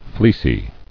[fleec·y]